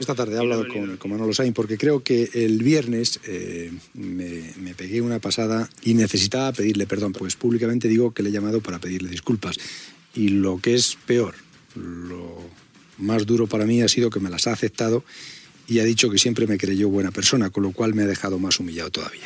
Comentari del presentador sobre Manolo Sáiz, director de la Vuelta Ciclista a España
Esportiu